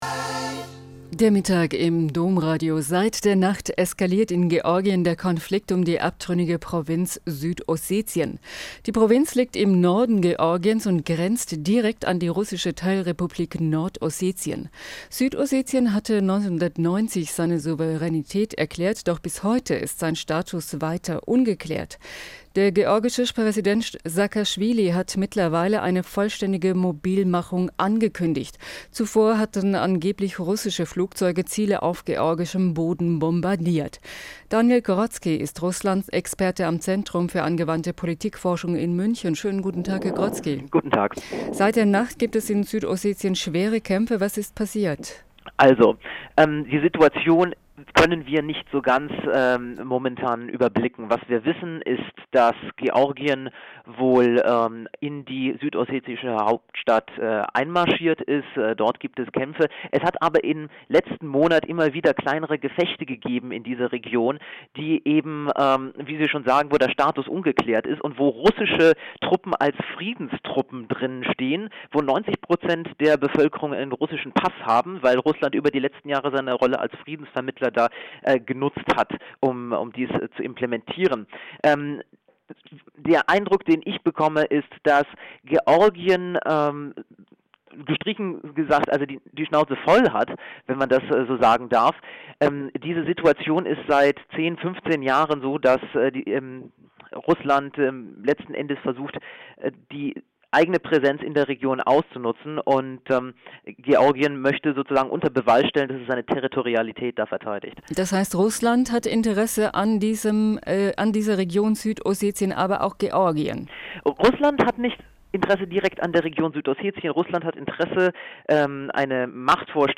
C·A·P Home > Aktuell > Interviews > 2008 > Krieg im Kaukasus